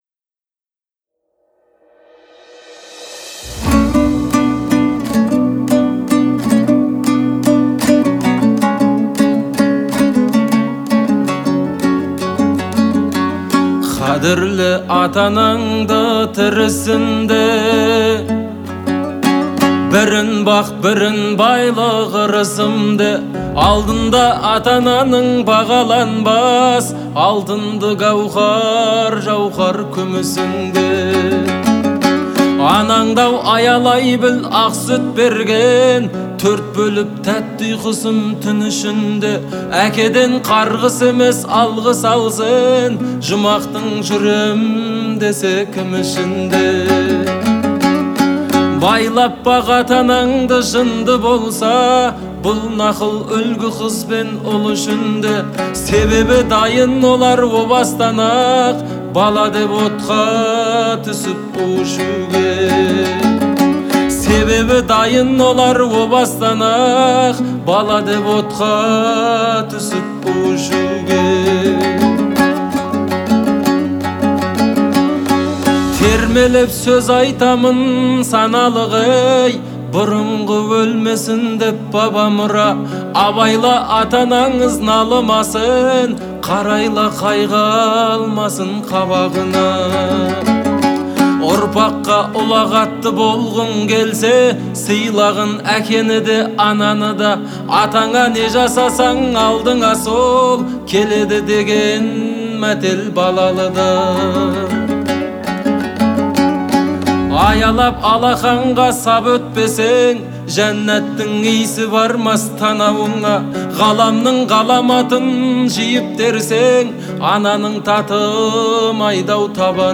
это трогательная композиция в жанре народной музыки